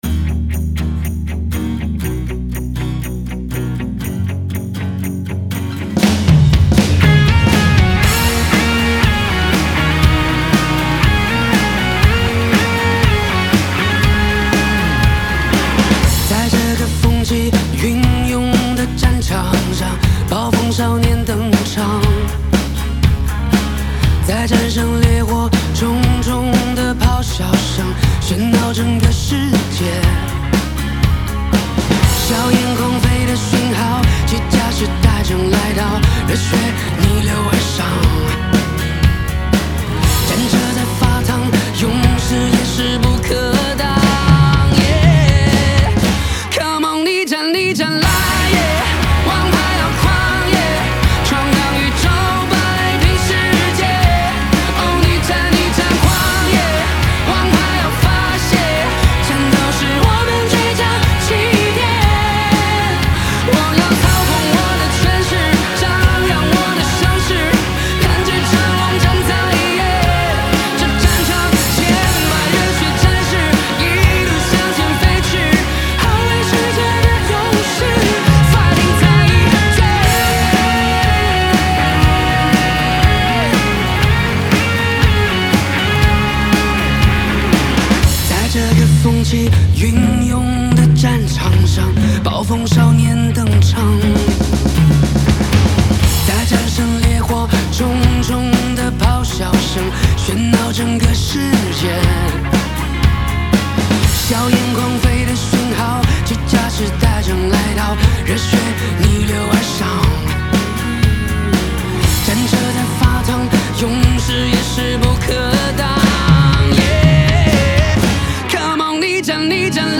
架子鼓